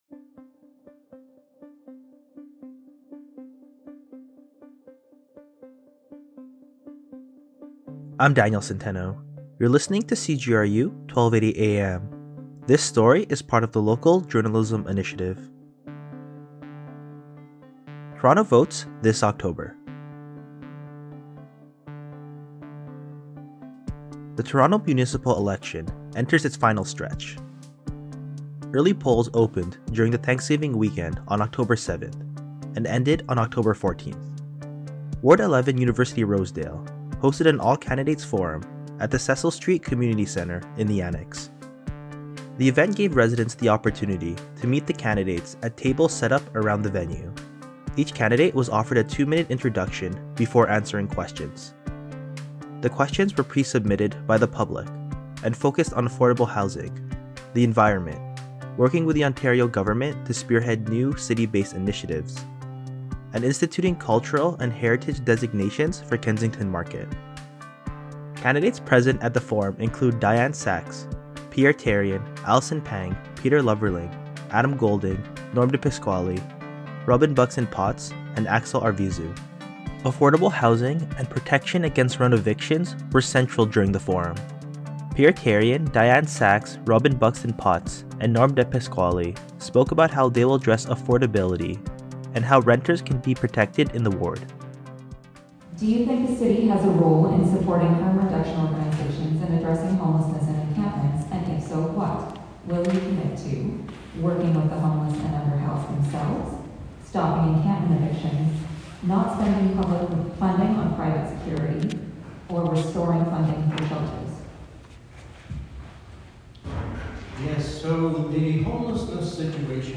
The Ward 11 University-Rosedale all-candidates forum, hosted at the Cecil St. Community Centre, also allowed residents to meet their candidates and speak with them one-on-one at each table around the venue.
Each candidate was given two minutes for an introduction before answering questions.